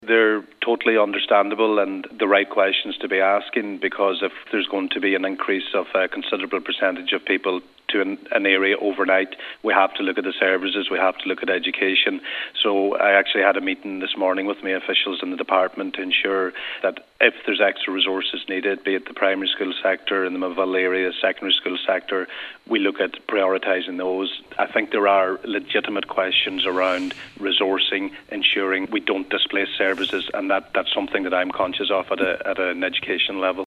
On today’s Nine til Noon Show, Education Minister Joe Mc Hugh said they are legitimate questions, and he and other ministers are taking notice…….